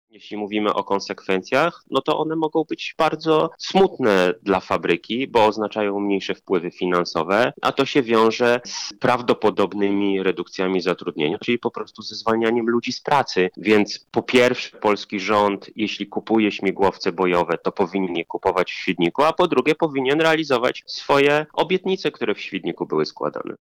Jeśli polski rząd zamawia śmigłowce, to ja bym bardzo chciał, żeby były zamawiane w Świdniku, a nie od Amerykanów – mówi poseł KO Michał Krawczyk.